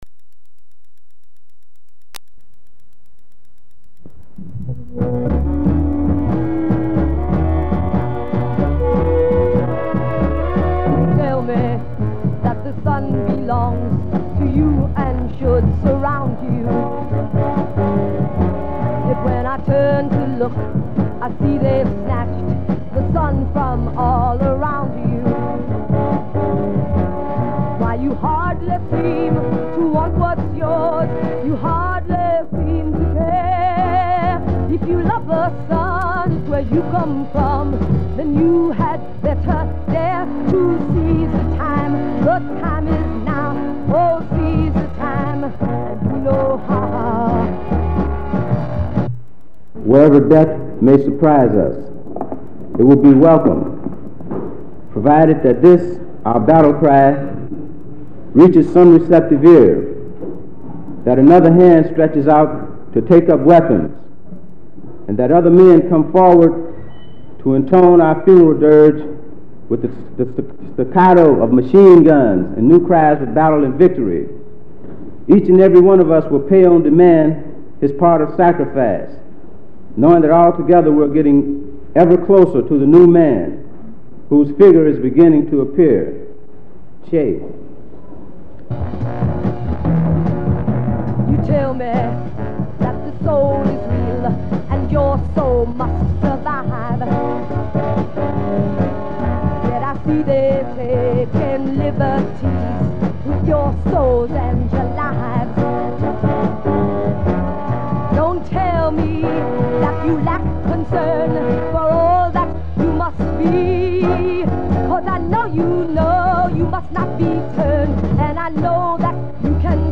Eulogy for Jonathan Jackson family and the William Christmas Family. [Audio 34 min]